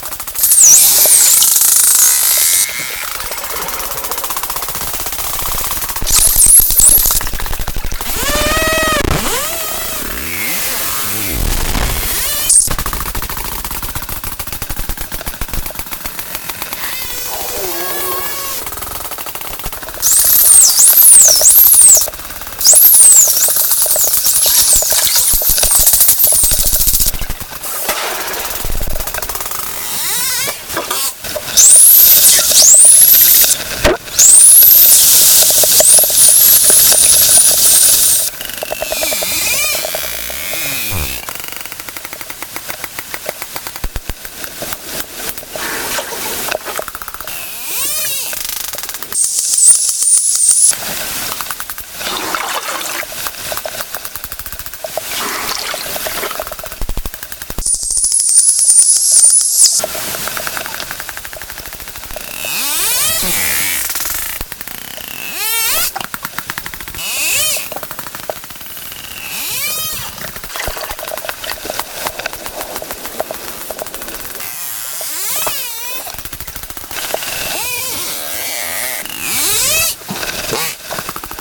Bunratty Dolphins  Edited.mp3